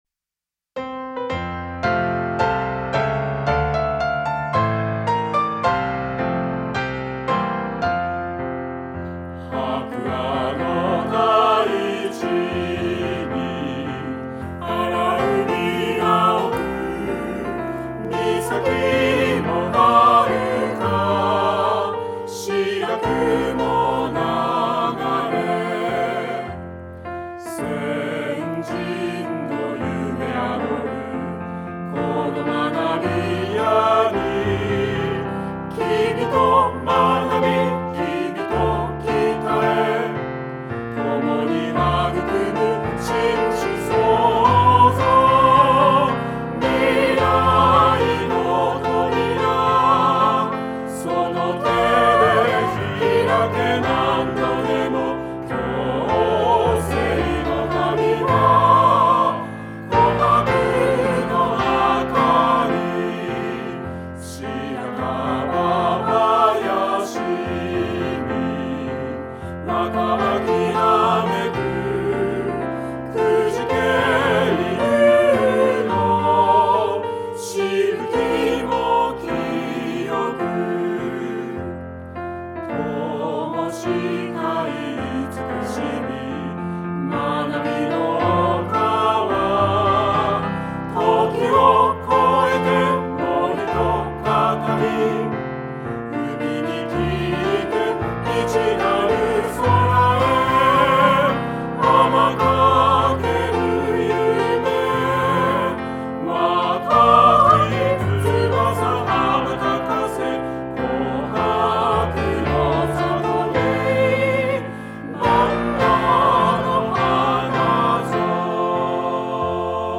翔北高校校歌四部合唱.mp3